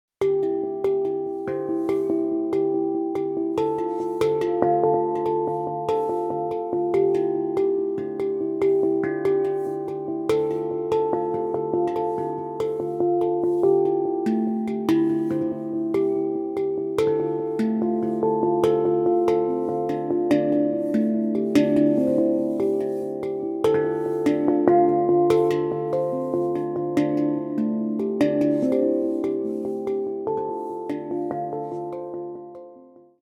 Handpan library
• Featuring the entire spectrum of pan sounds
Mournful, expressive percussion